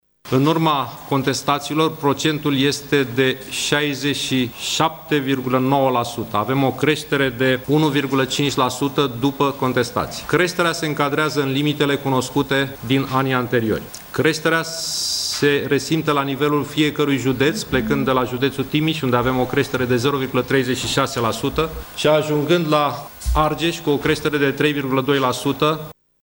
Este cu peste şapte procente mai mare decât anul trecut, dar se încadrează în limitele din anii anteriori, a anunţat ministrul Educaţiei , Sorin Câmpeanu: